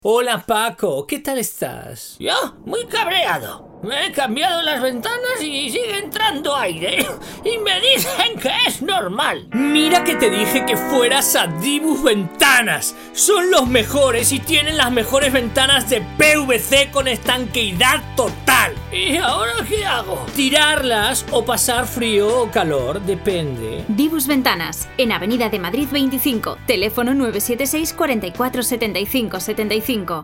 Entrevista en Radio Sariñena SER
sdibus_ventana_viento2.mp3